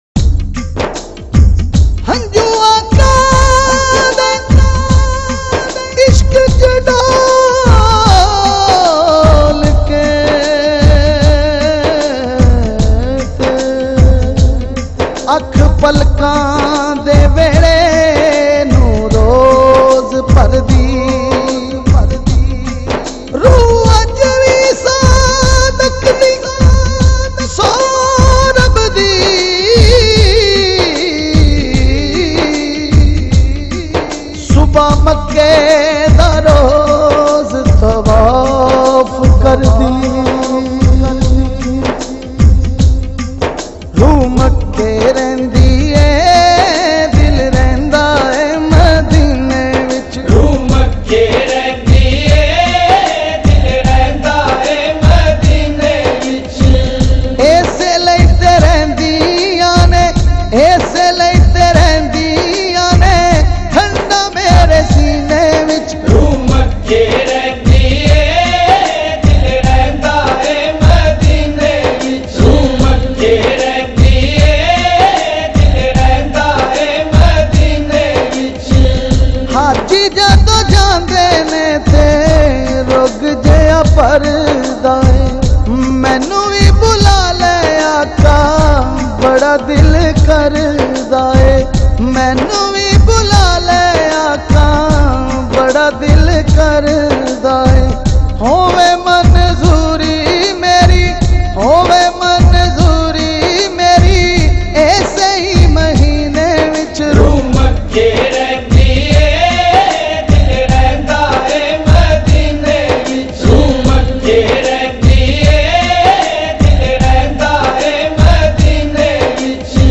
in best audio quality